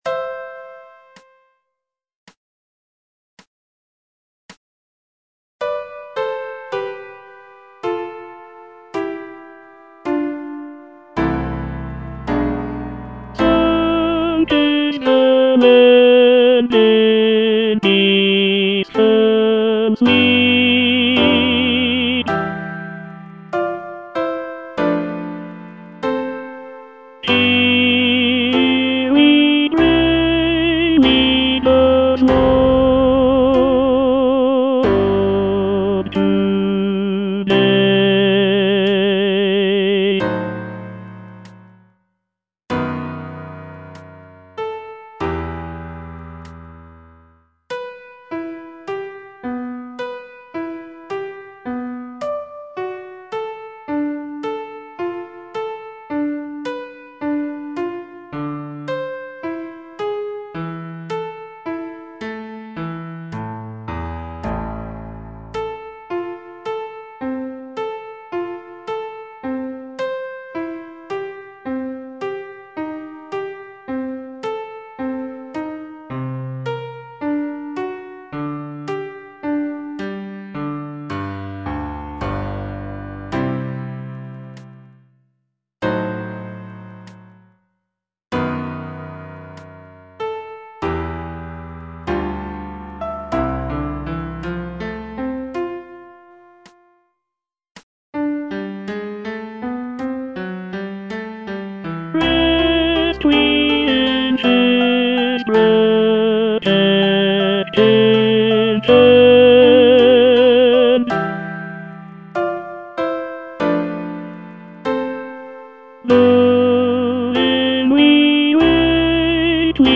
E. ELGAR - FROM THE BAVARIAN HIGHLANDS Aspiration (tenor I) (Voice with metronome) Ads stop: auto-stop